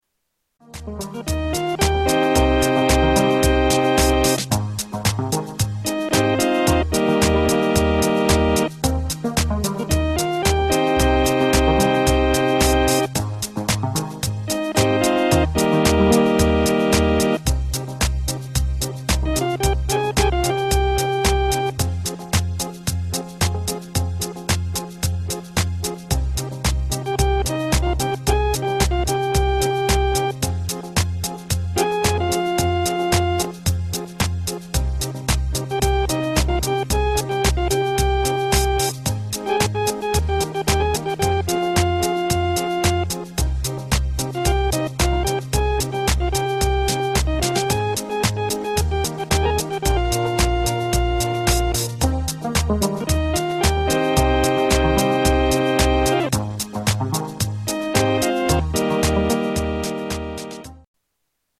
ELKA Panther 100
Category: Sound FX   Right: Personal